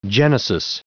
Prononciation du mot genesis en anglais (fichier audio)
Prononciation du mot : genesis